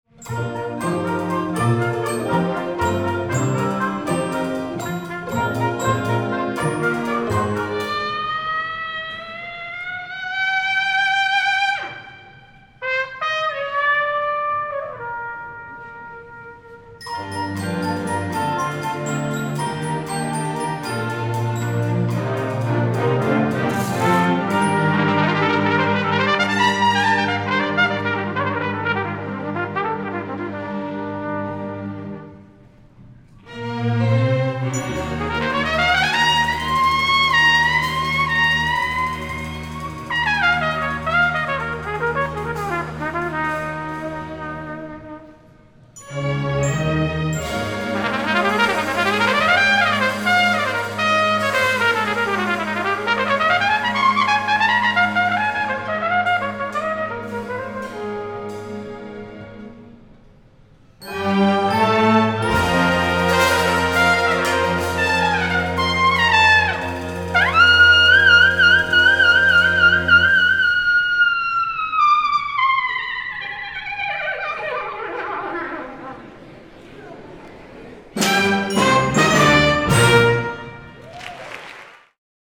One of my favorites is a piece commissioned for a children’s concert – an audience of 5000 upper elementary students is an awesome opportunity to play with musical ideas in an unusual way. I was asked to write a Theme and Variation style piece for orchestra and soloist that included improvisational opportunities for jazz trumpeter and composer Sean Jones, new to our faculty at that time.
I’ll let Sean Jones take it home in the finale of “Pop!” (and the sound of 5000 kids laughing)